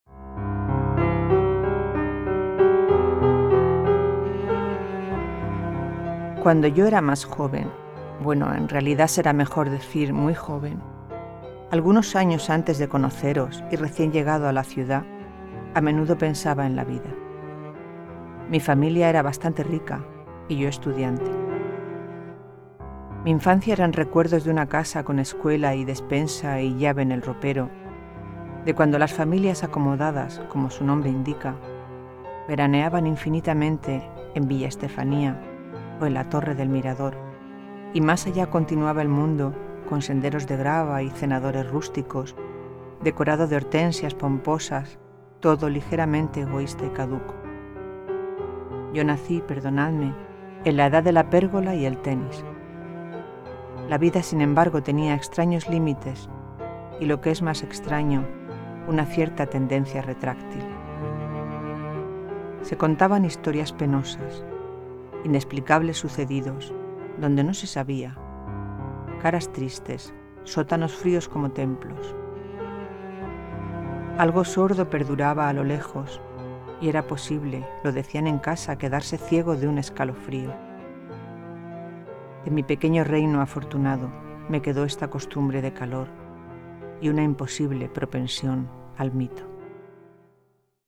poema
recitar